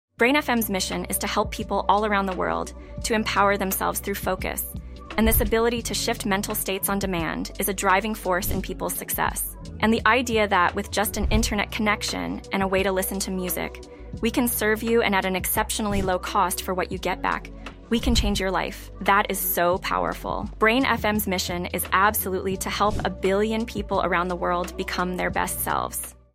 Put on your headphones, sink into the soundscape, and let the music guide your brainwaves to your happy place.